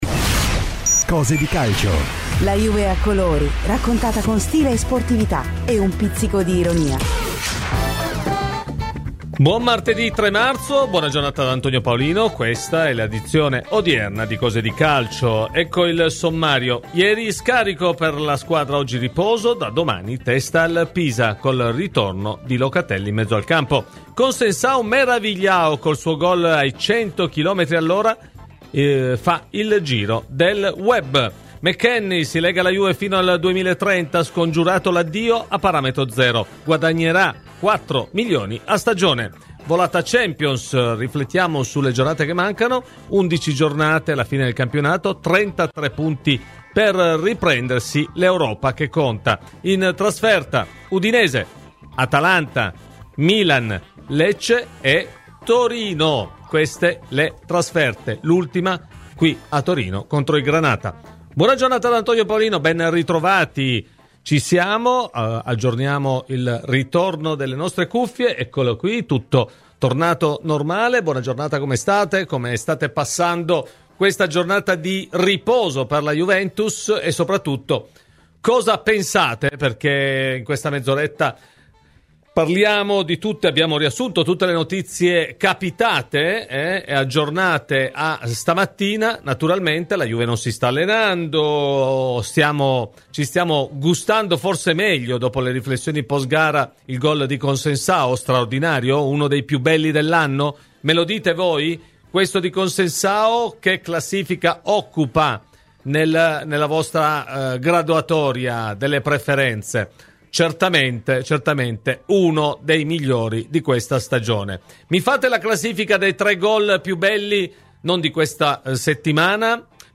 Ospite di "Cose di Calcio" su Radio Bianconera